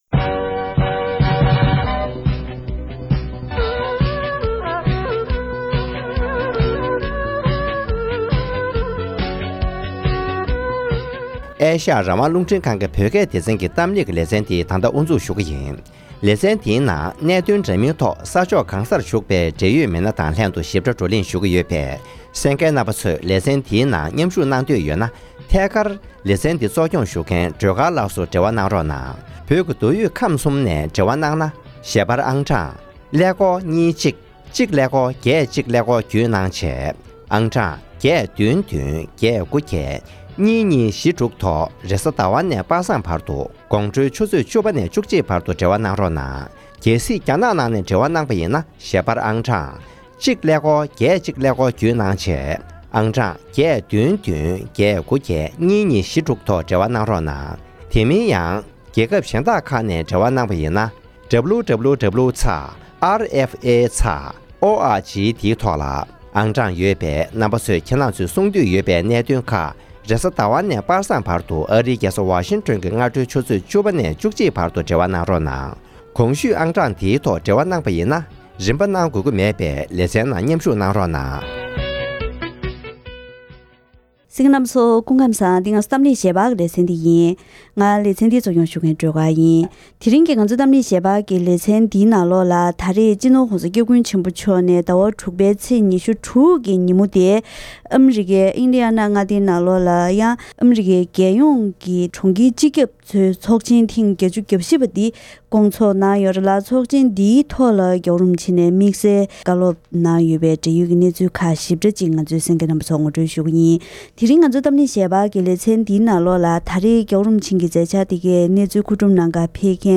ཨ་རིའི་གྲོང་ཁྱེར་སྤྱི་ཁྱབ་ཀྱི་ཚོགས་ཆེན་ཐོག་༧གོང་ས་མཆོག་ནས་བཀའ་སློབ་གནང་བ།